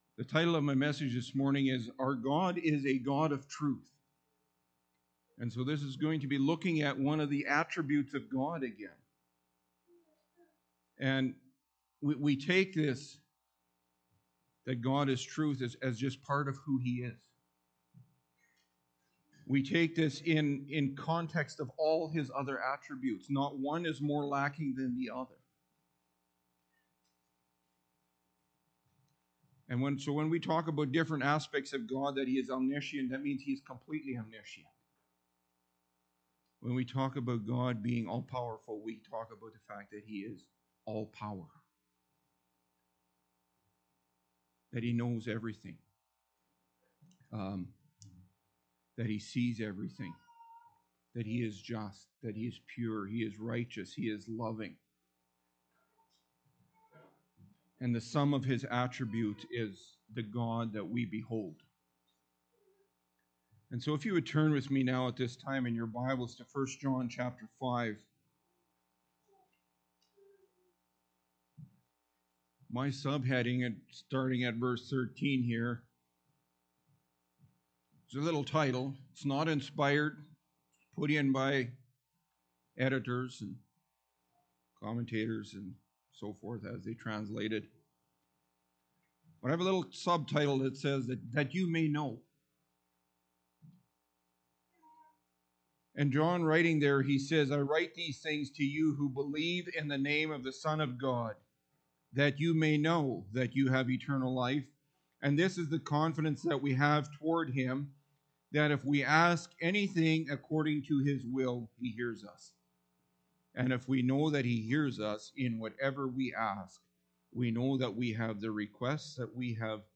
Attributes of God Category: Pulpit Sermons https